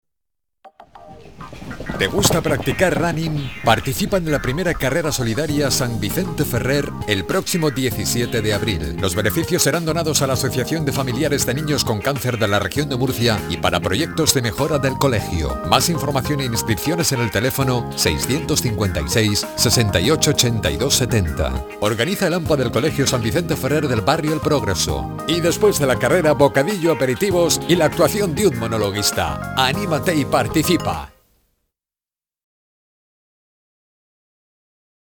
Anuncio en la radio de Carrera Benéfica